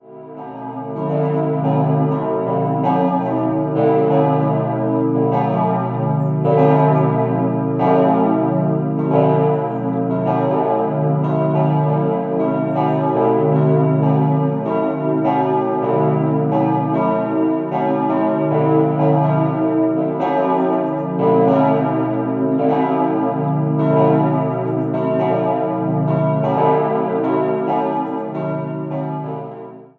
Auffällig ist der weithin sichtbare Doppelhelm-Turm. 3-stimmiges Geläute: ais°-cis'-e' Die Glocken wurden im Jahr 1895 beim Bochumer Verein für Gussstahlfabrikation gegossen.